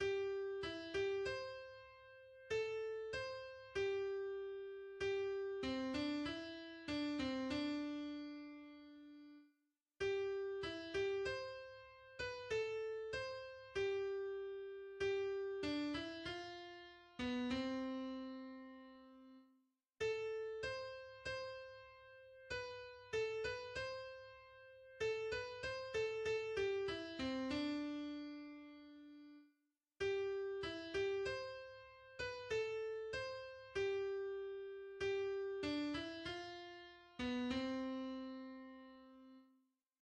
大東正聯邦國歌_長亭.mp3.ogg